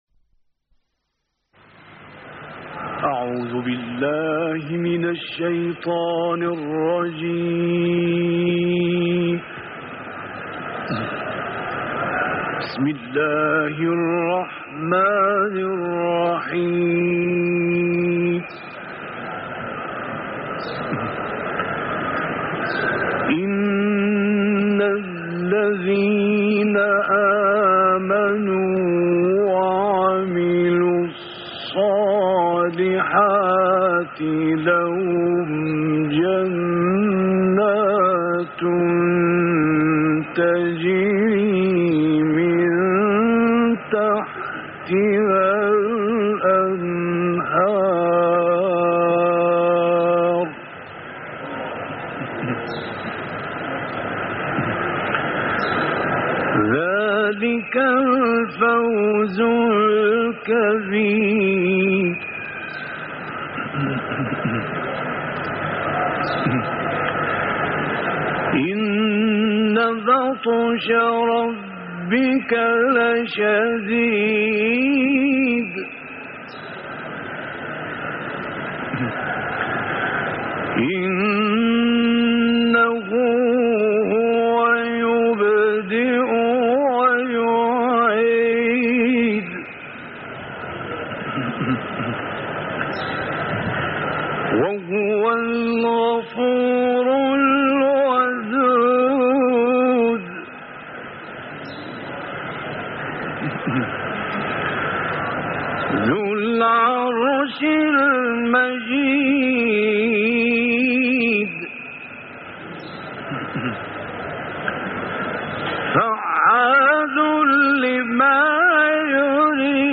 دانلود قرائت سوره رحمان آیات 9 تا 23 - استاد راغب مصطفی غلوش